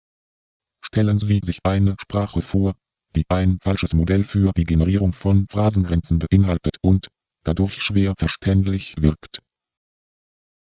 Für das Setzen dieser Grenzen müssen Regeln aufgestellt werden, denn: Stellen Sie# sich eine# Sprache vor, die ein# falsches Modell für# die Generierung von# Phrasengrenzen beinhaltet und, dadurch schwer# verständlich# wirkt (